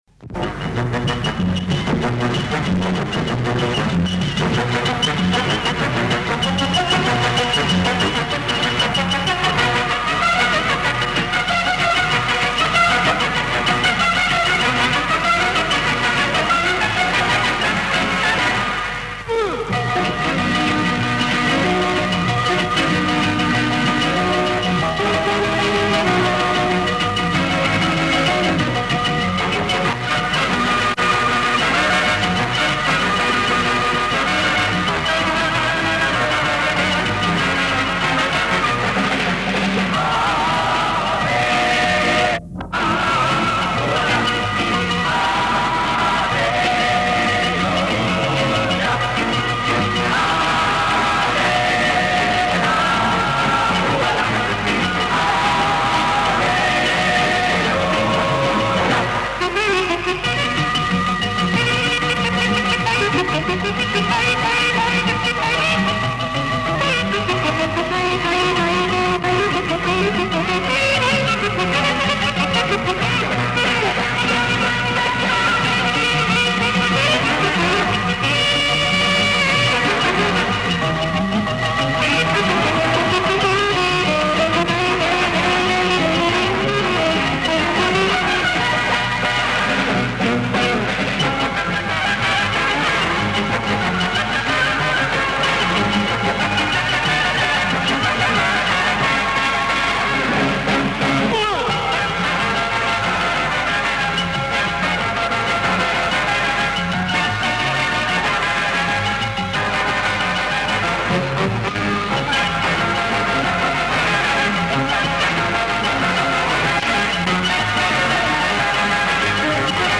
Предупреждаю – качестве жутчашее низко, поэтому берегите уши :)